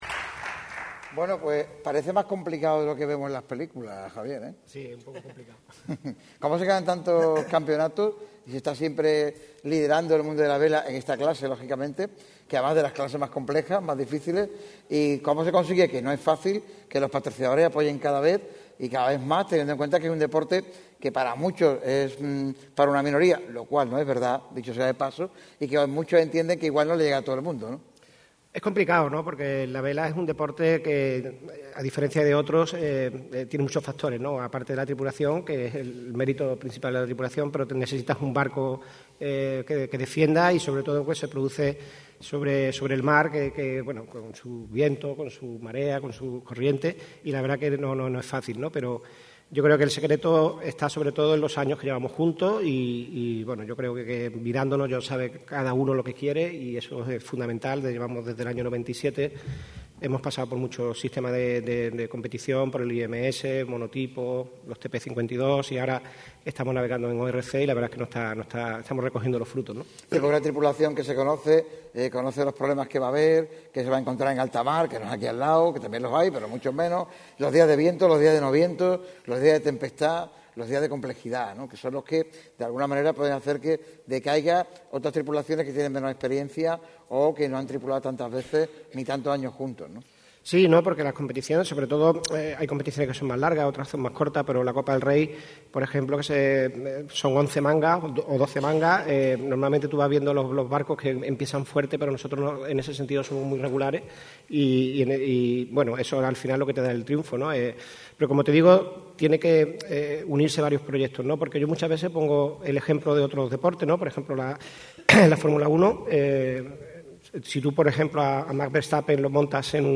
sobre el escenario del Auditorio Edgar Neville de la Diputación de Málaga con motivo de la celebración de la XXIV gala de aniversario.